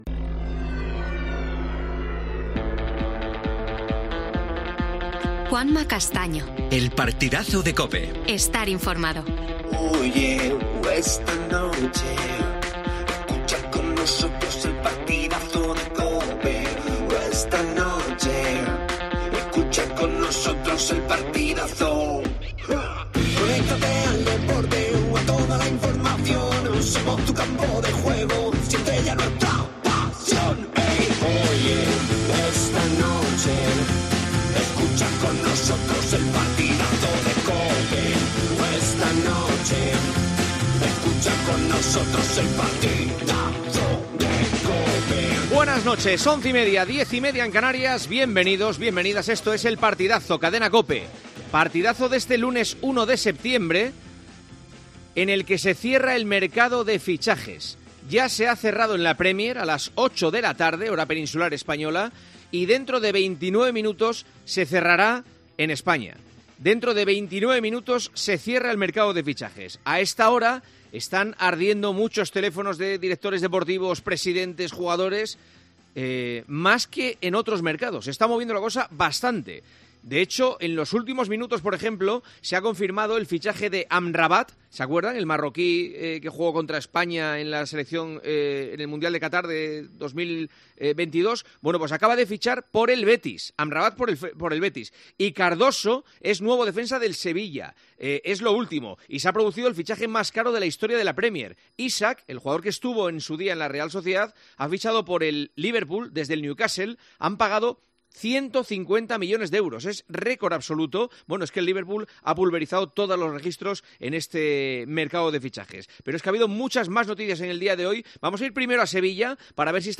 Careta i cançó del programa. Data, informació sobre el tancament del mercat de fixatges de La Lliga de Futbol Professional, Connexió amb Sevilla
Esportiu